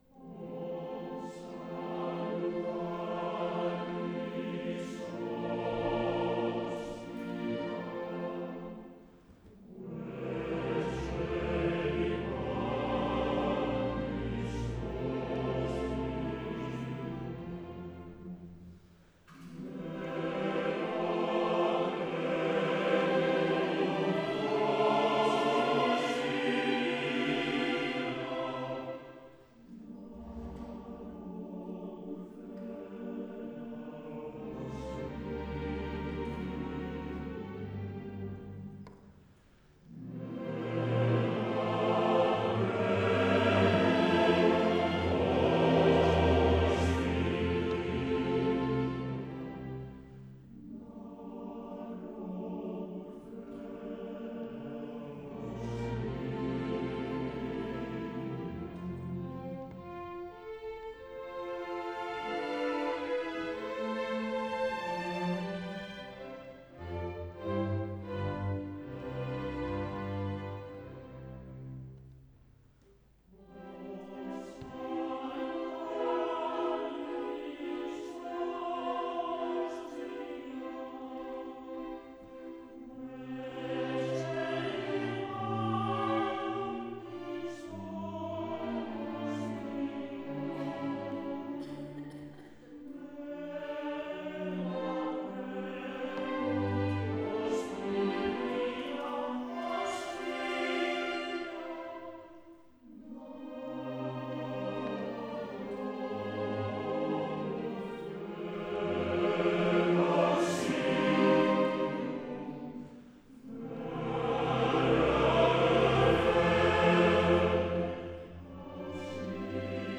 Concierto de clausura.